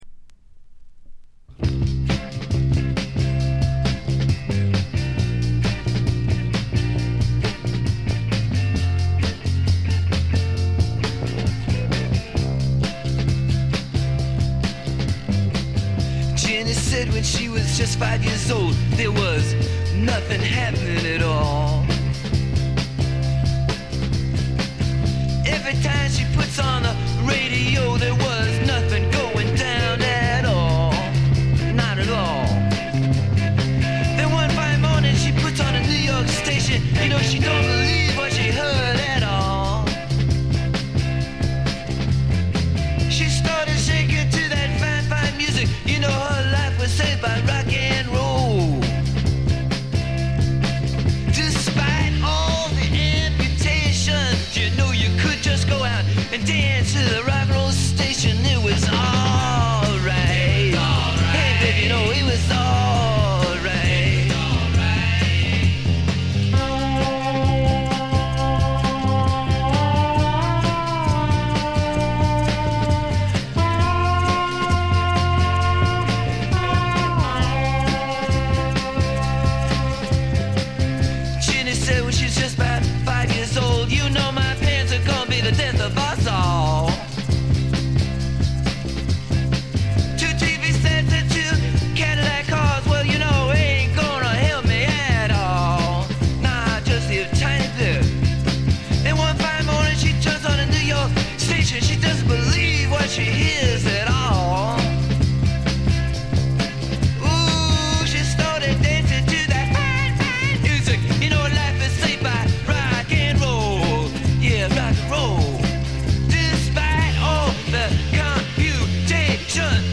静音部で軽微ななチリプチが少々。
試聴曲は現品からの取り込み音源です。